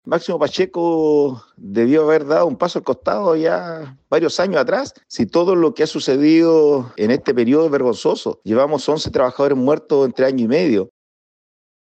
Desde el oficialismo, el diputado de la bancada del PPD e integrante de la misma comisión, Cristián Tapia, sostuvo que Máximo Pacheco debió haber dejado el cargo hace varios años.